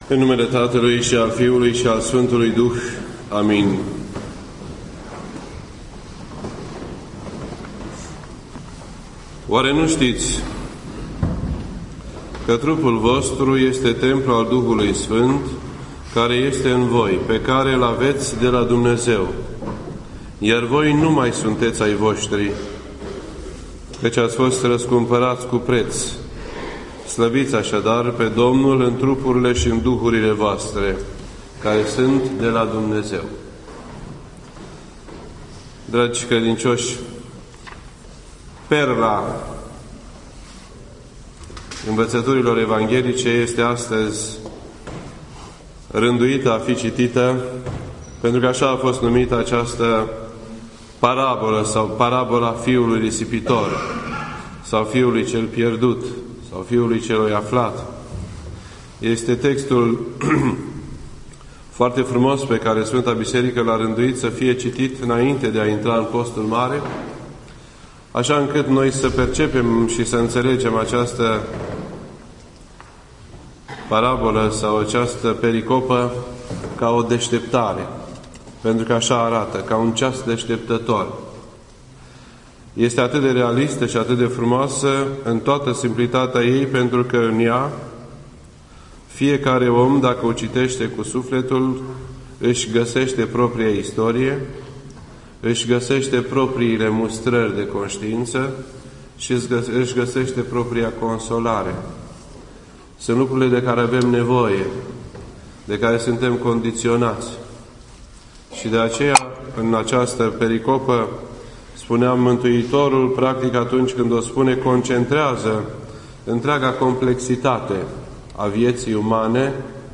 This entry was posted on Sunday, March 3rd, 2013 at 8:31 PM and is filed under Predici ortodoxe in format audio.